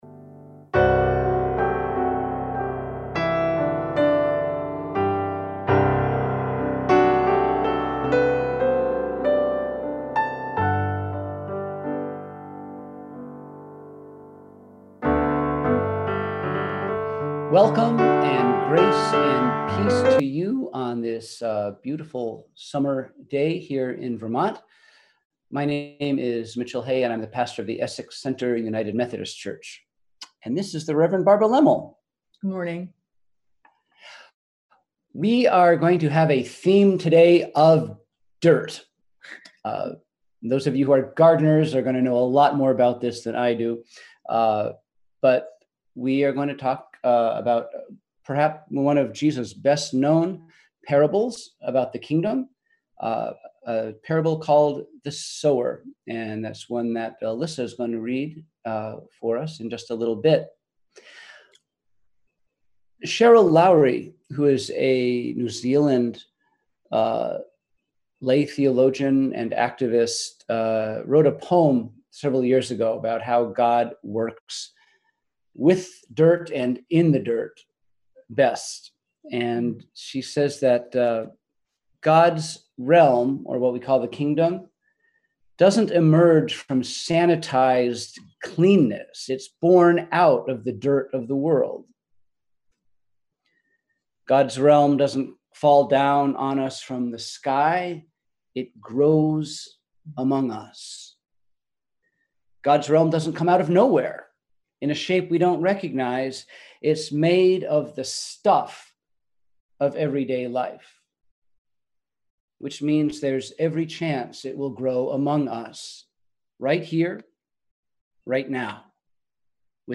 We held virtual worship on Sunday, July 12, 2020.